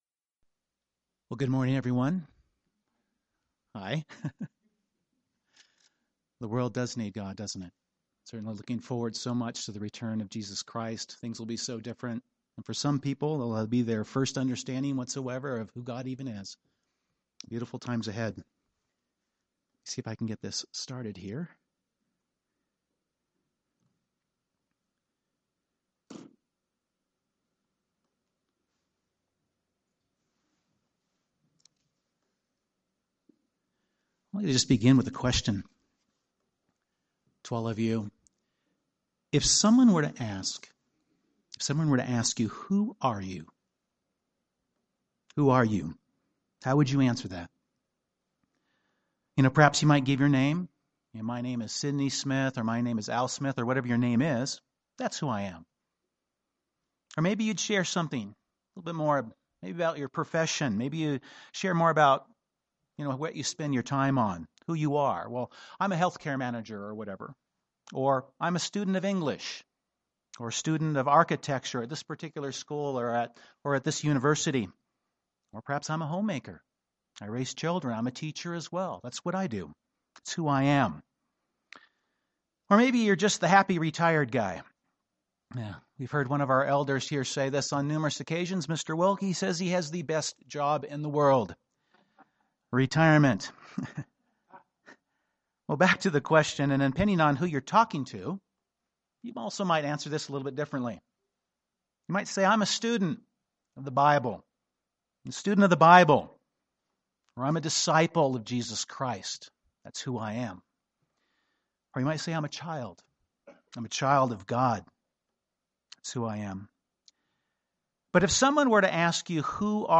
The bible uses salt to symbolize many things. This sermon focuses on the symbolism of salt with a particular focus on what Jesus Christ taught about salt and what it means to be the “salt of the earth” (Matthew 5:13).